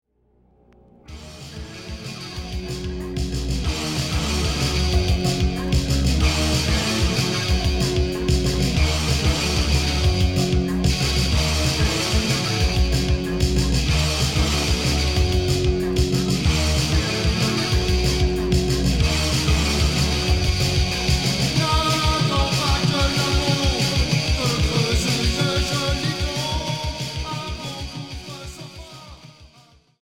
Punk Alternatif